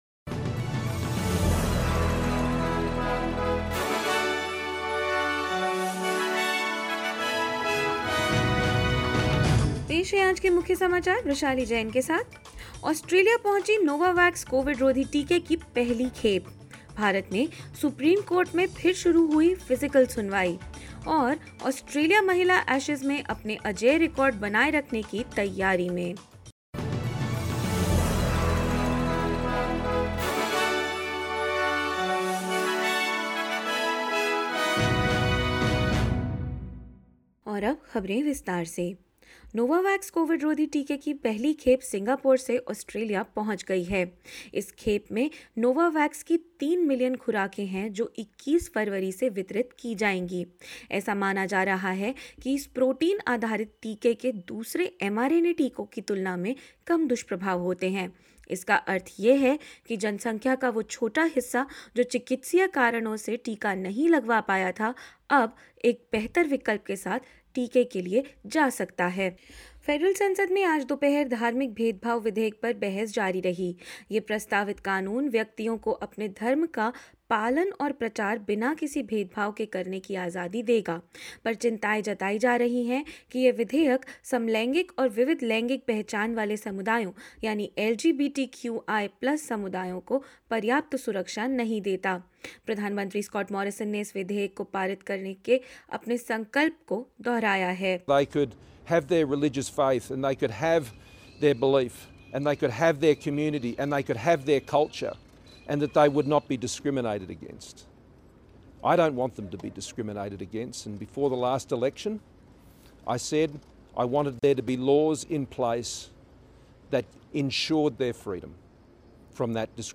SBS Hindi News 08 February 2022: Parliament debates the Religious Discrimination Bill as diverse communities voice their concerns